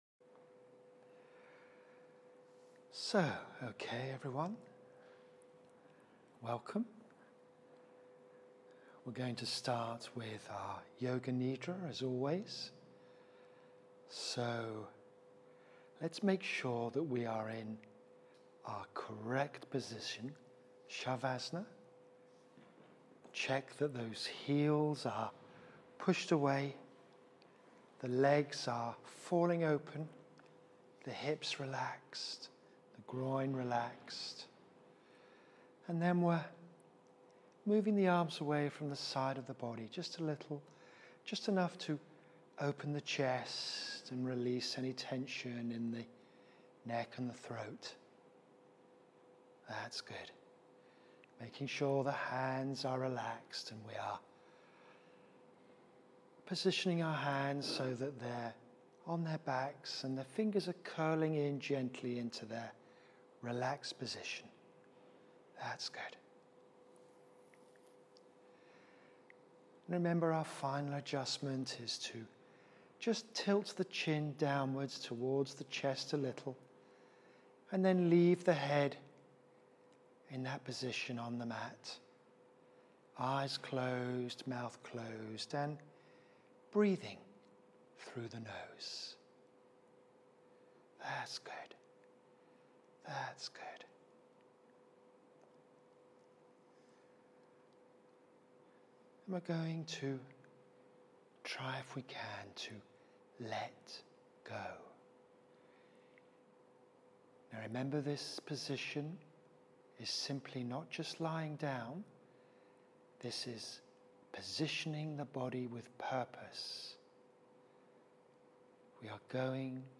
Download your FREE YLP Yoga Nidra Audio guide here
Streamed Yoga Nidra